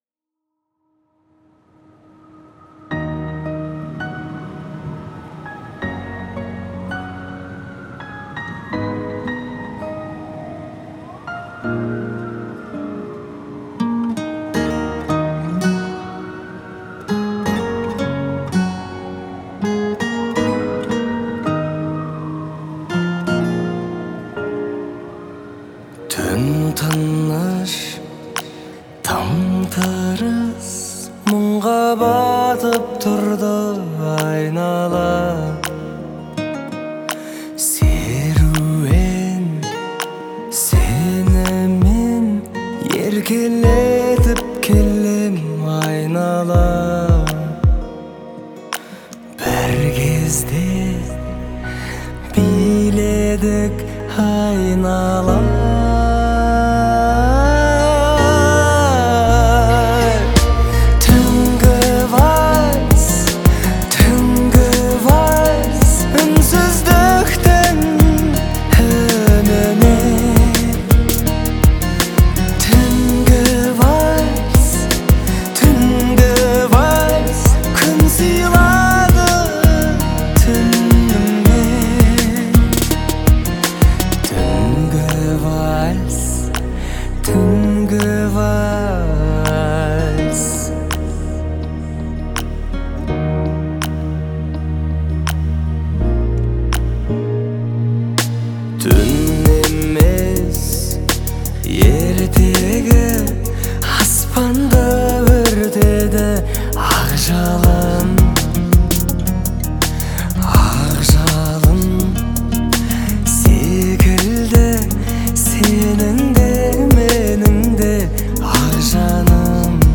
это романтическая композиция в жанре поп